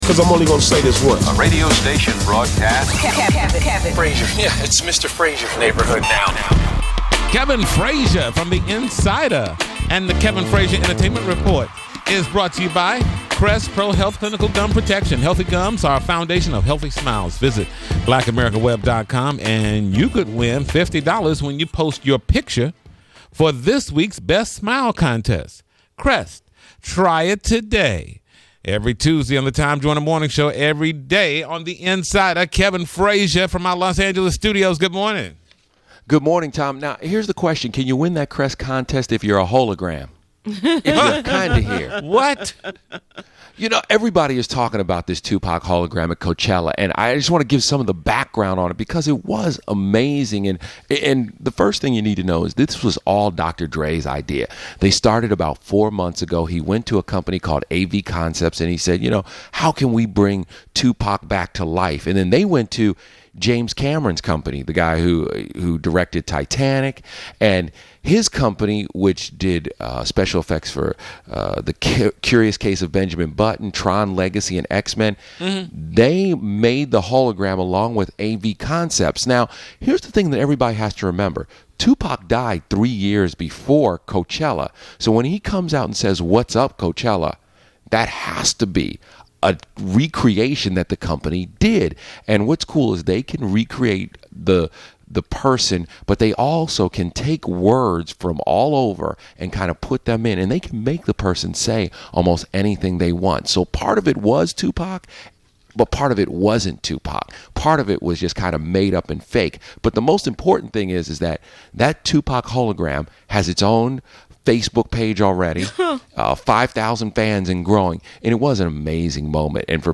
Kevin Frazier’s Entertainment Report On Coachella And Tupac [AUDIO]